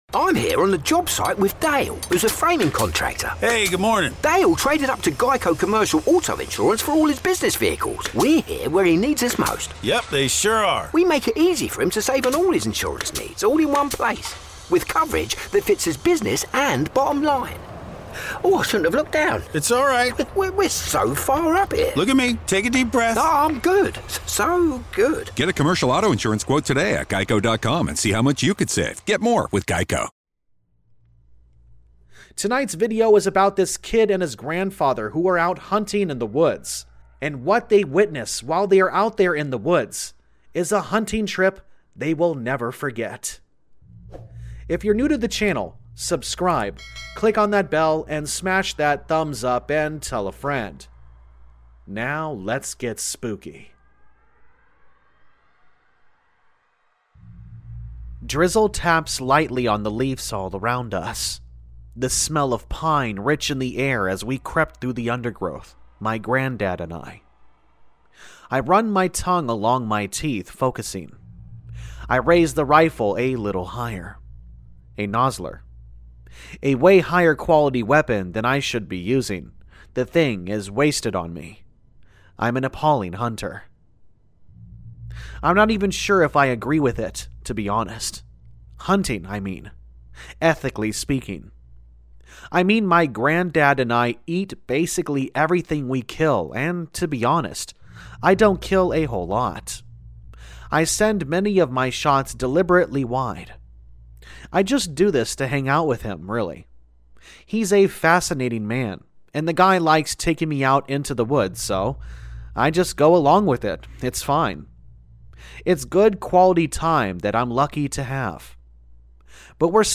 Campfire Tales is a channel which is focused on Allegedly True Scary Stories and Creepypastas.